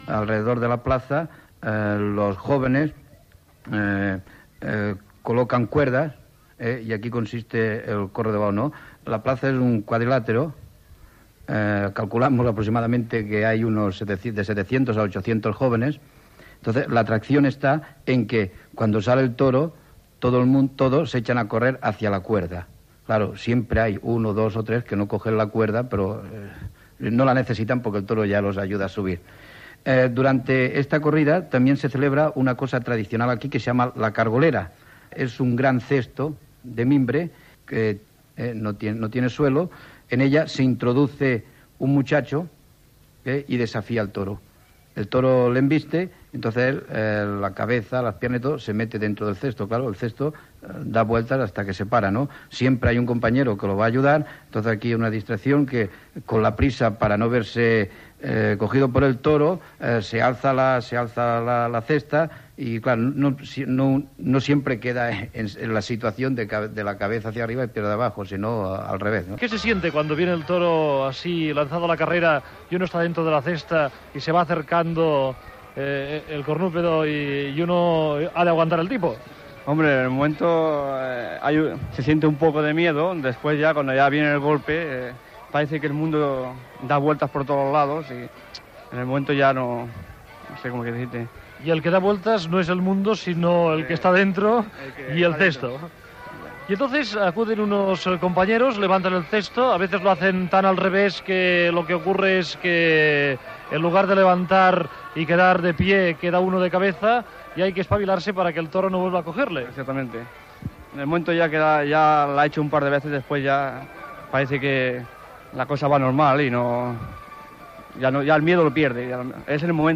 Reportatge sobre el correbous de Cardona i la cargolera